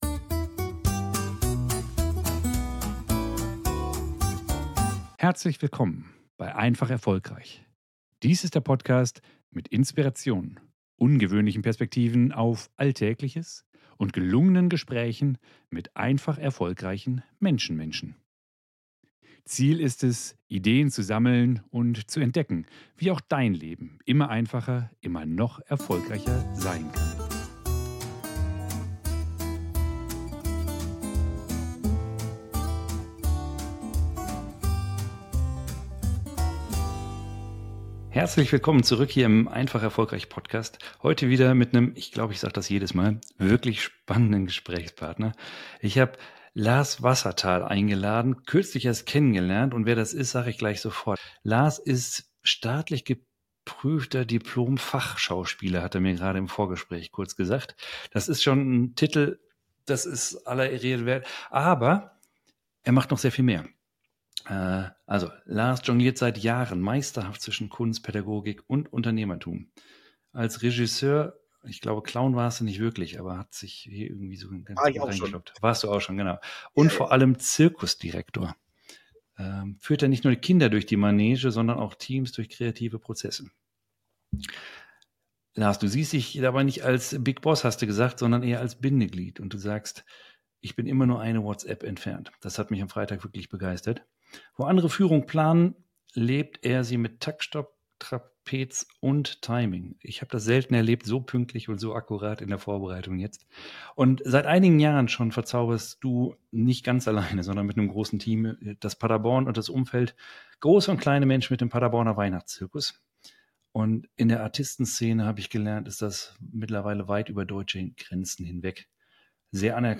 Wir tauchen in ein spannendes Gespräch ein und beleuchten die fundamentalen Unterschiede zwischen der Führung in der Künstlerwelt und in klassischen Unternehmen.